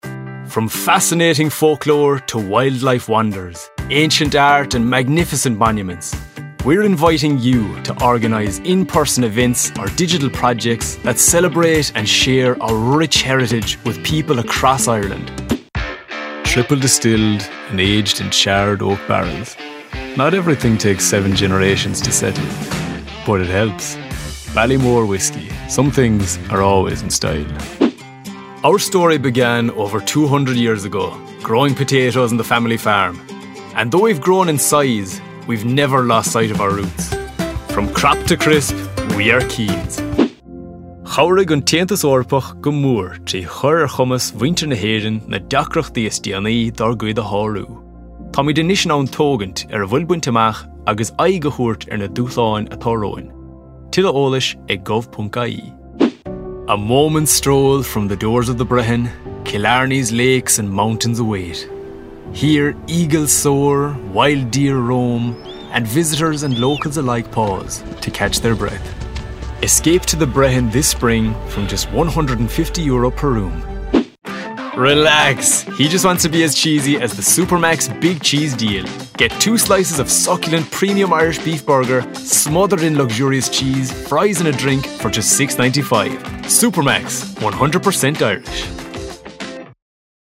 Male
Voice Qualities
20s/30s